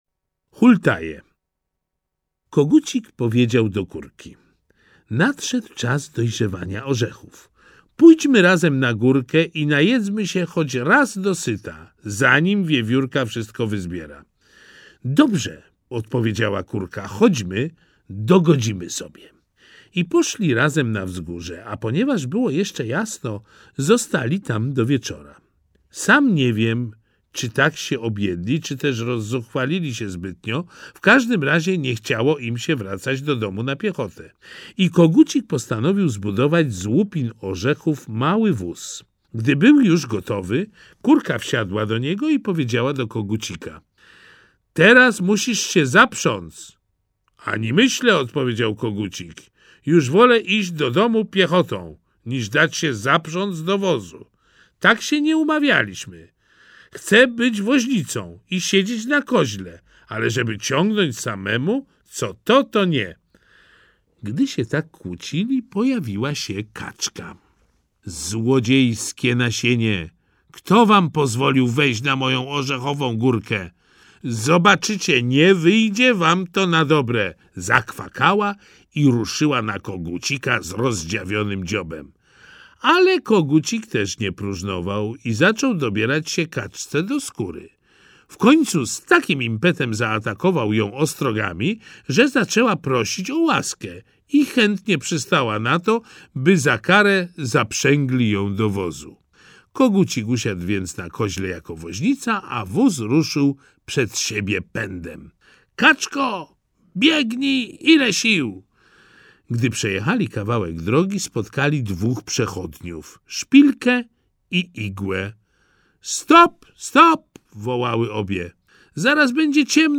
Baśnie braci Grimm 1 - Jakub Grimm, Wilhelm Grimm - audiobook